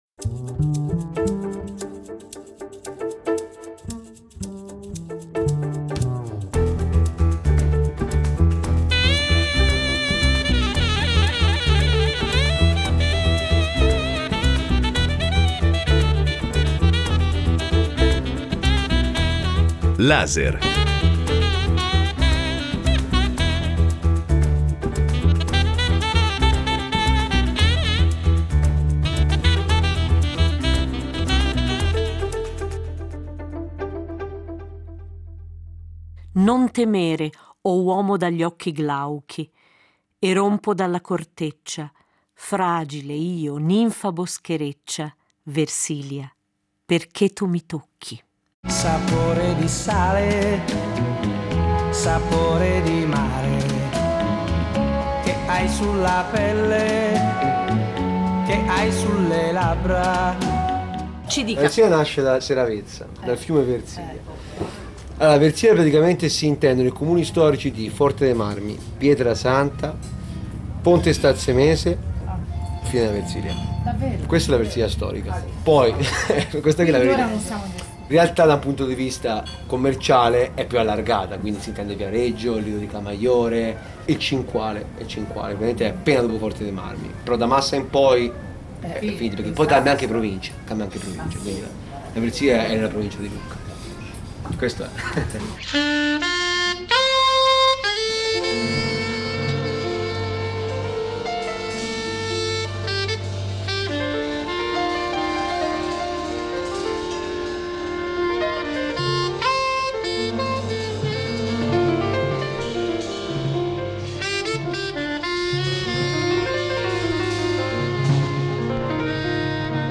la Versilia è terra di festival e premi letterari, colta e modaiola, frequentata dai cosiddetti vips , lanciata negli anni ’60-70 da Mina che animava con la sua voce inimitabile le serate alla Bussola, leggendario locale notturno sul lungomare di Marina di Pietrasanta, la Versilia che ha la sua perla nella ricca e costosa Forte dei Marmi, ma che guarda anche a vere e proprie città come Viareggio, la Miami della costa per i suoi fantastici e autentici edifici liberty, ebbene la Versilia, si diceva, è terra di contrasti e di abbracci, come sentiremo in questo Laser, nato in una sera d’estate, catturando la conversazione di una brigata di amici che la frequentano abitualmente, e di qualche voce…fuori dal coro!